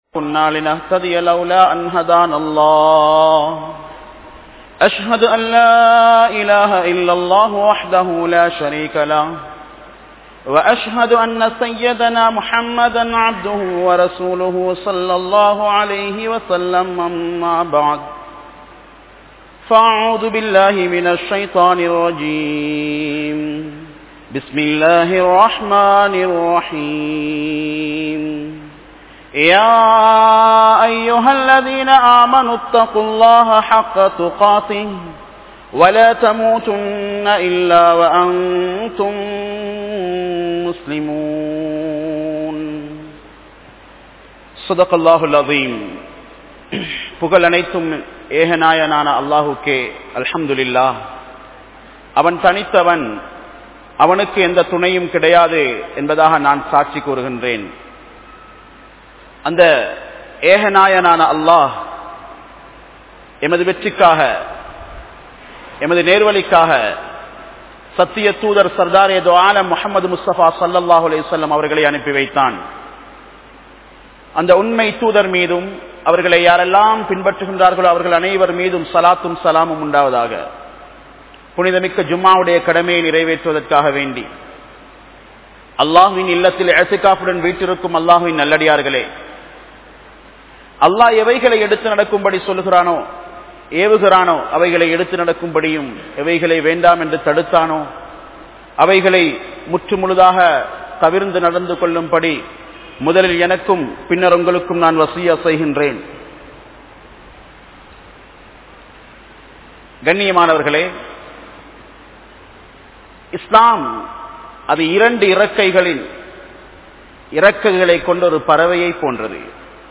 Petroarhalai Uirudan Kolai Seium Pillaihal(பெற்றோர்களை உயிருடன் கொலை செய்யும் பிள்ளைகள் | Audio Bayans | All Ceylon Muslim Youth Community | Addalaichenai
Kandy, Line Jumua Masjith